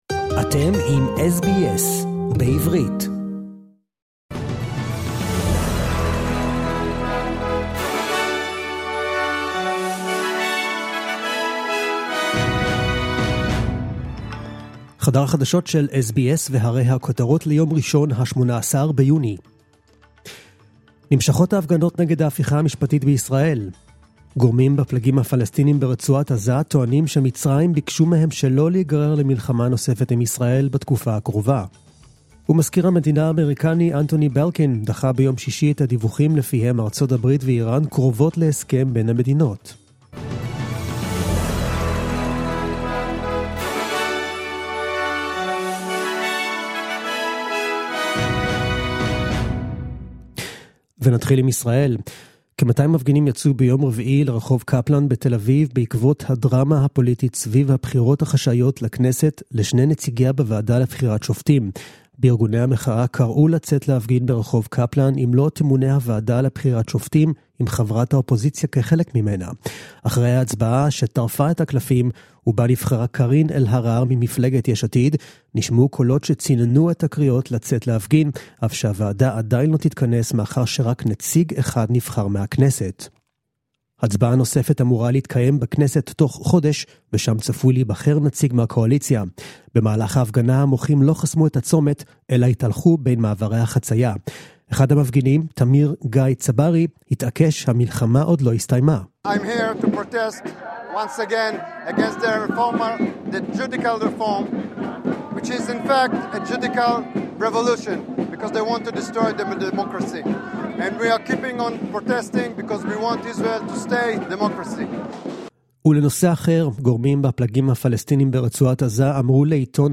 The latest news in Hebrew, as heard on the SBS Hebrew program